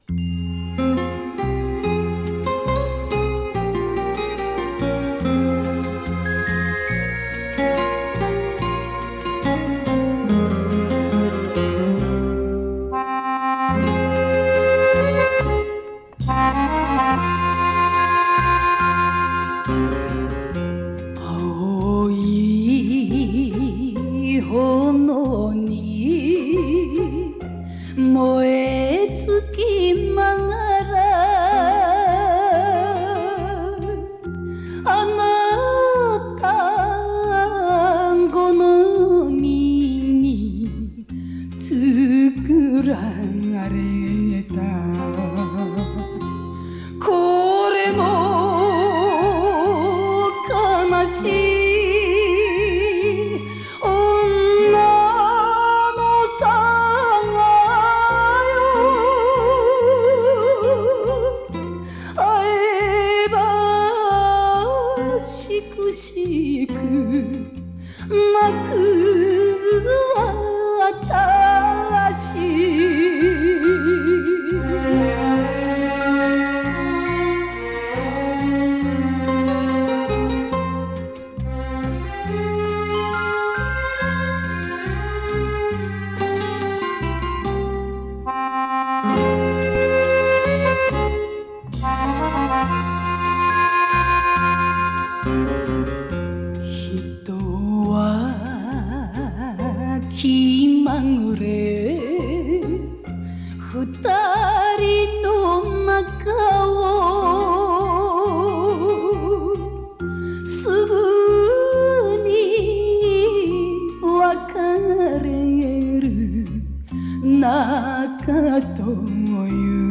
picЖанр: Enka